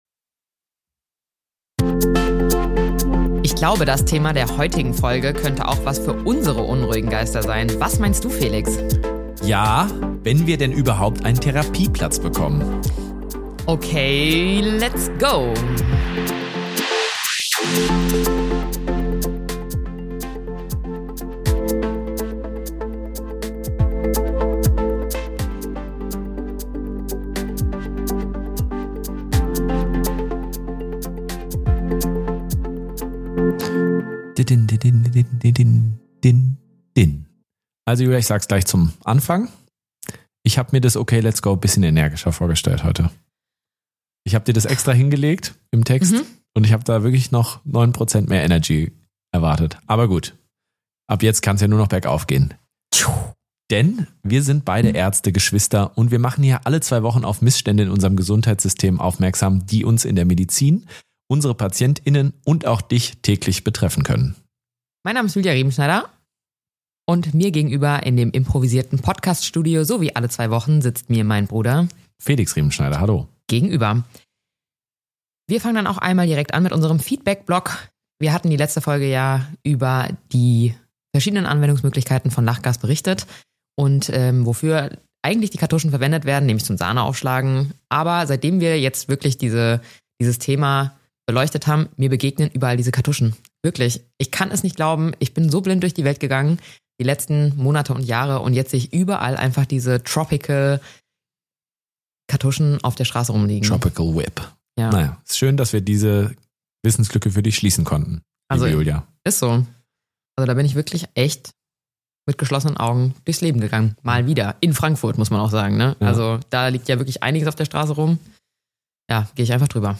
Im Hinblick auf so viele Probleme sind wir froh auch in dieser Woche wieder einen Gast begrüßen zu dürfen.